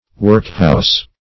Workhouse \Work"house`\, n.; pl. Workhouses. [AS.